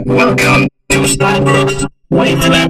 经过Vokator处理后的人声：
vocoder2.mp3